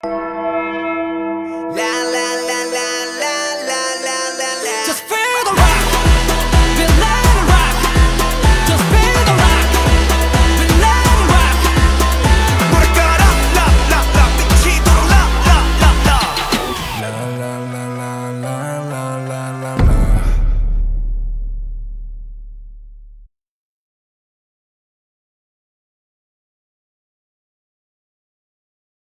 Скачать музыку / Музон / Корейская K-POP музыка 2024